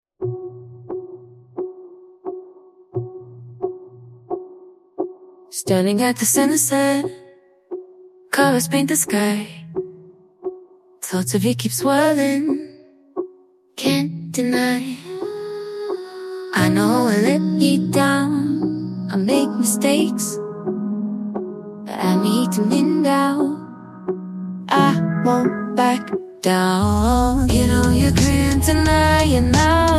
multilingual music-generation singing-voice-generation text-to-music
Generate music with YuE-s1-7B (English, chain of thought model)
Genre tags: inspiring female uplifting pop airy vocal electronic bright vocal vocal